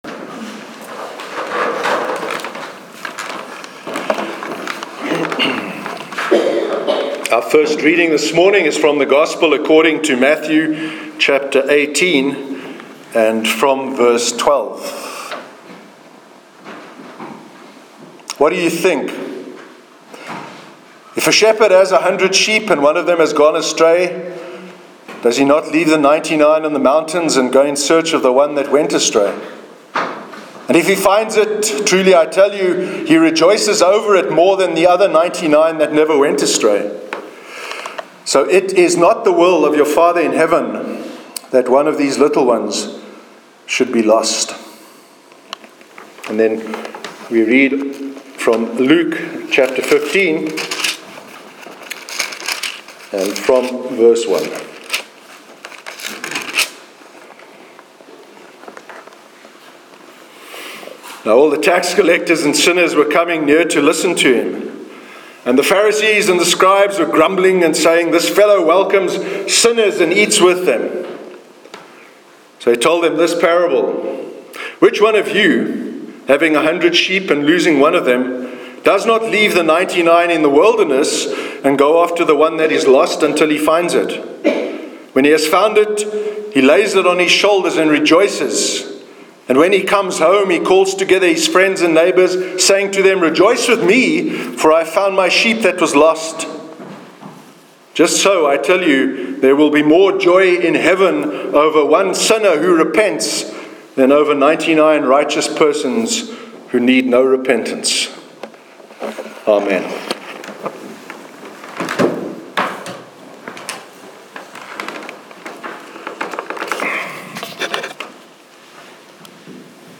Sermon on the Parable of the Lost Sheep- 6th August 2017
sermon_6th_august_2017.mp3